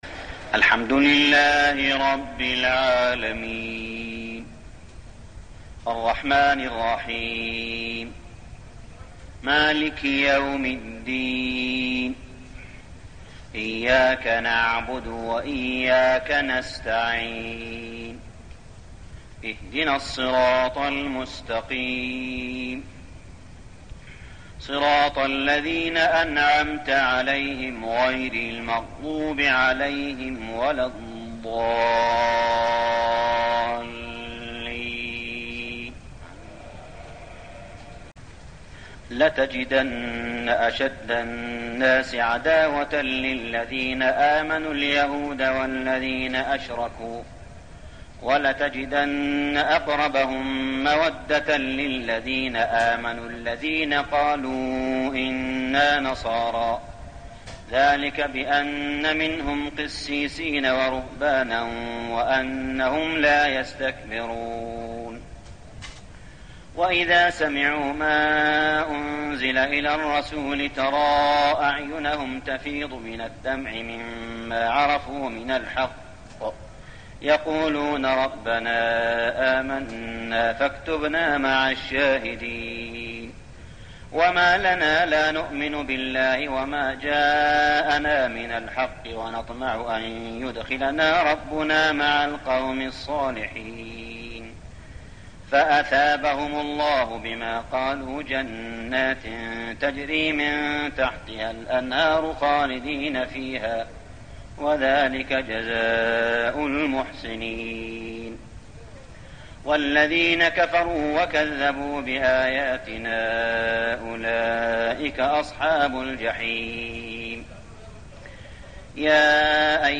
صلاة التراويح ليلة 8-9-1410هـ سورتي المائدة 82-120 و الأنعام 1-35 | Tarawih prayer Surah Al-Ma'idah and Al-An'am > تراويح الحرم المكي عام 1410 🕋 > التراويح - تلاوات الحرمين